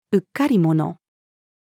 うっかり者-female.mp3